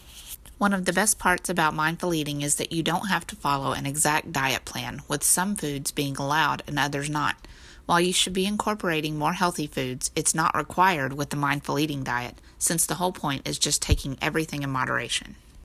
I can hear a big difference between how I was reading scripts before and how I do it now.
Listen to how I read this paragraph before I took any lessons: